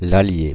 Ääntäminen
Ääntäminen France (Île-de-France): IPA: [a.lje] Paris: IPA: [a.lje] France (Île-de-France): IPA: [l‿a.lje] Haettu sana löytyi näillä lähdekielillä: ranska Käännöksiä ei löytynyt valitulle kohdekielelle.